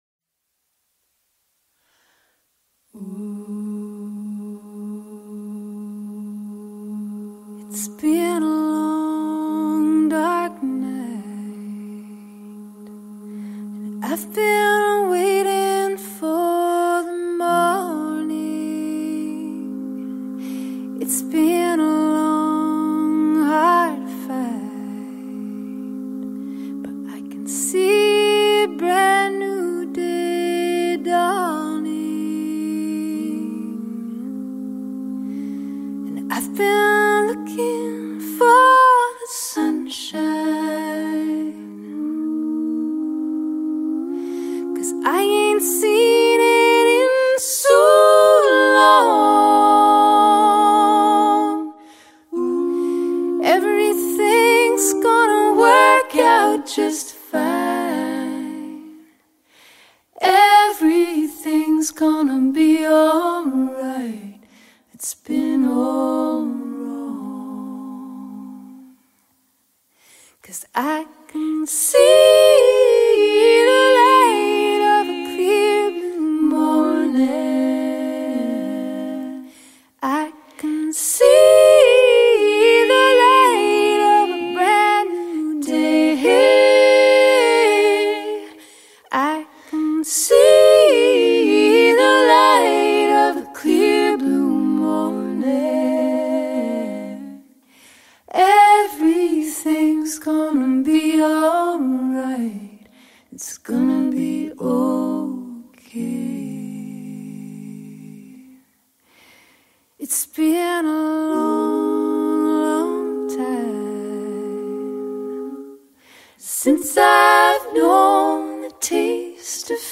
acapella folk version